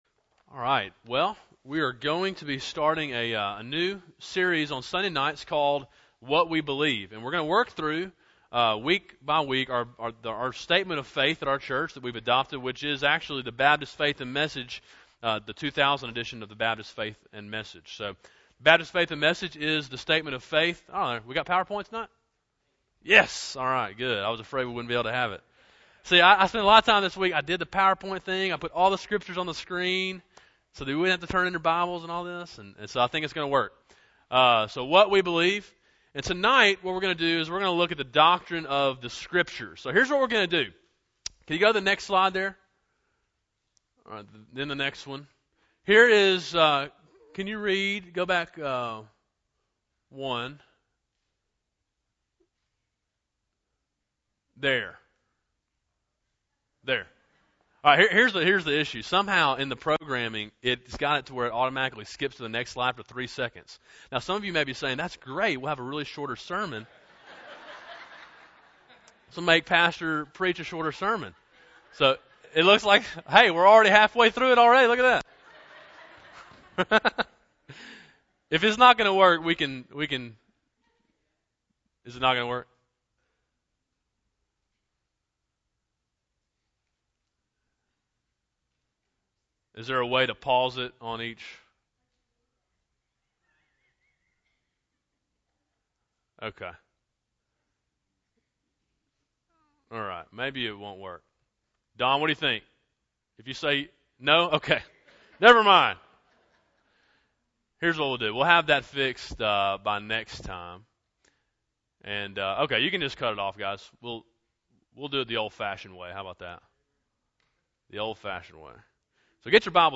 Sermon Audio: “The Scriptures”